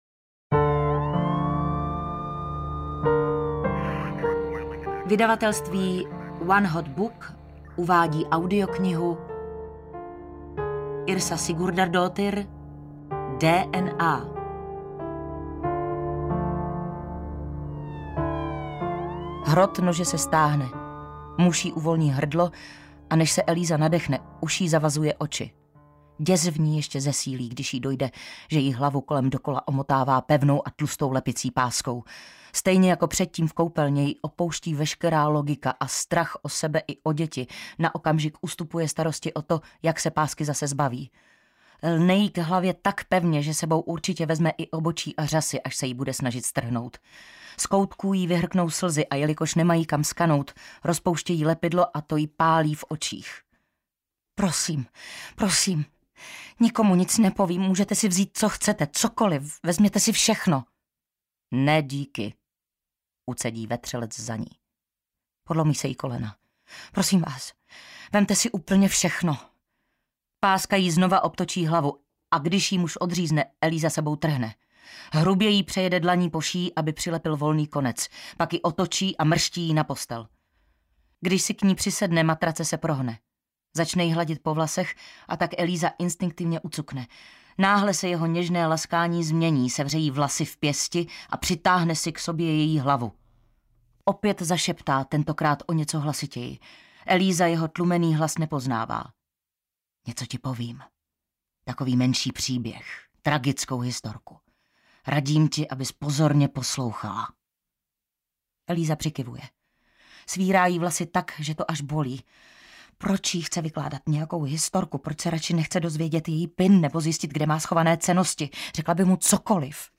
DNA audiokniha
Ukázka z knihy
• InterpretKlára Cibulková